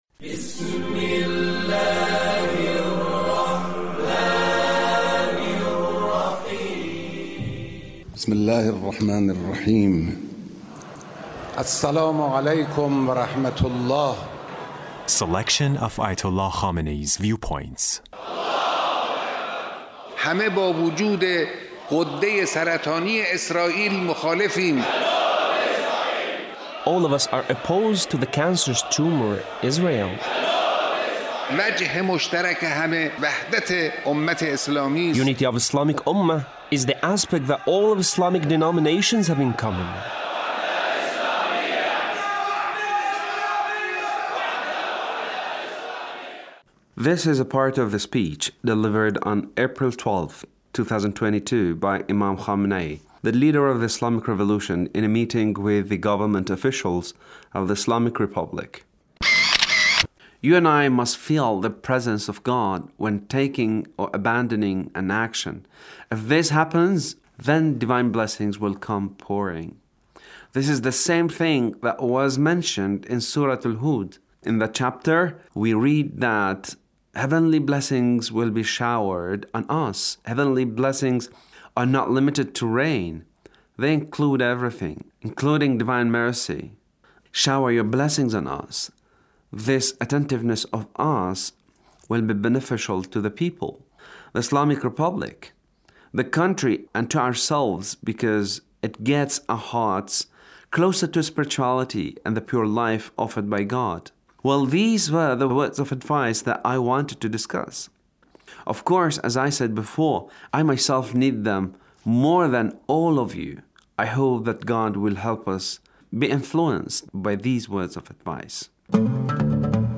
Leader's speech (1378)